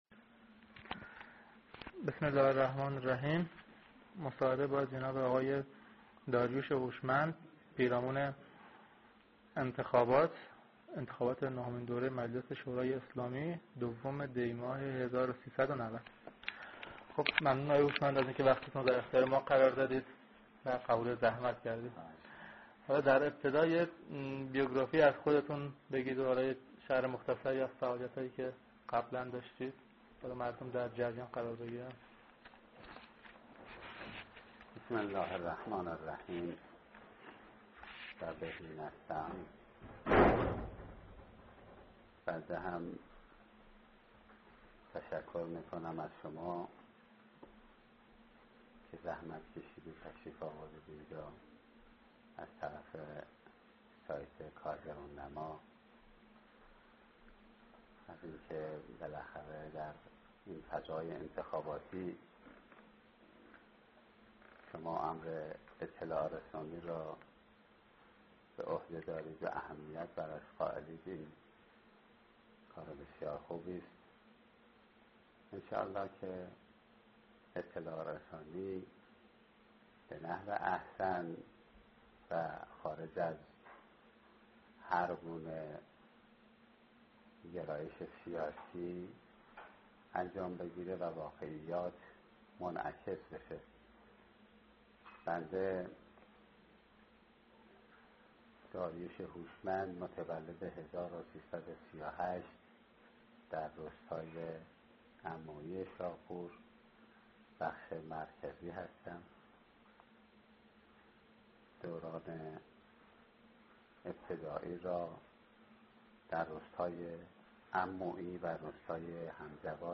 فایل صوتی: مصاحبه